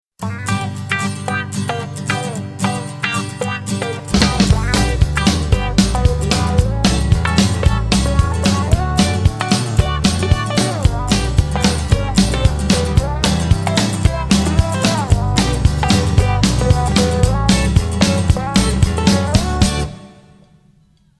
MP3 Demo Instrumental Version